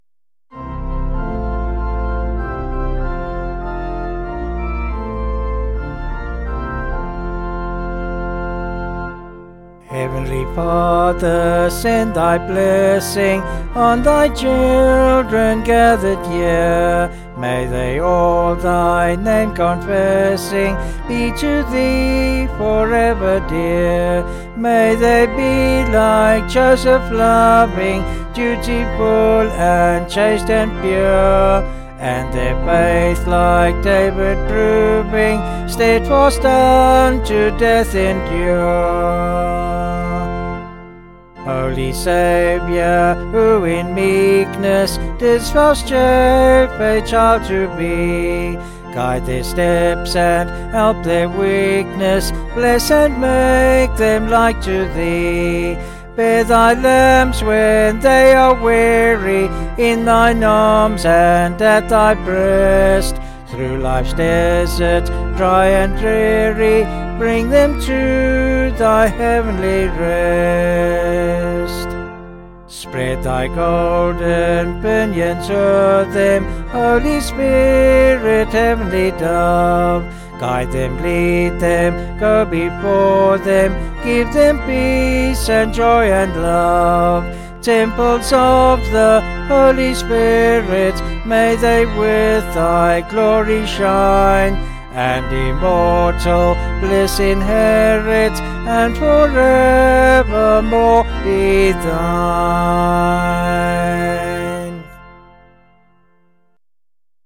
Vocals and Organ   702.2kb Sung Lyrics